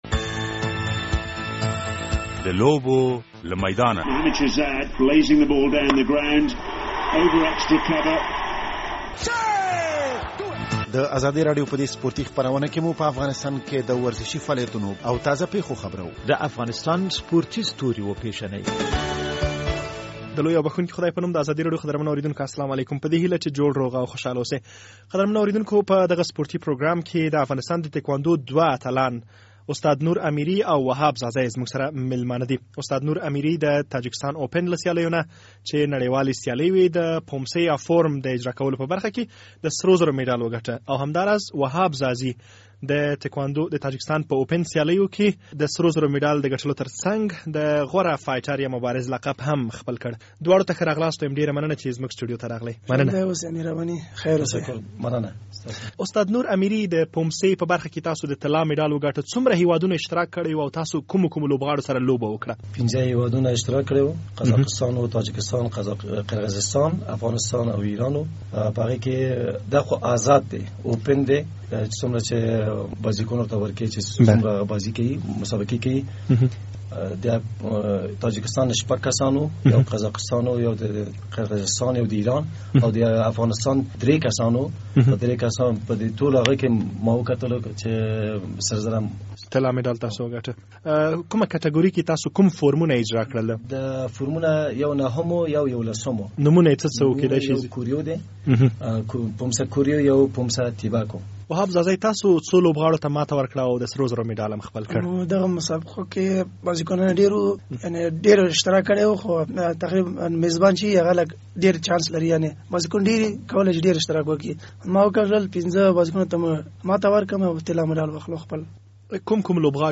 د لوبو له میدانه سپورټي پروګرام خپرېدو ته چمتو دی.